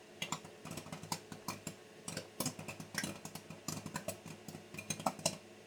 Cabritas | SÓNEC | Sonoteca de Música Experimental y Arte Sonoro
Cabritas saltando al cocinarse
Sonidos Domésticos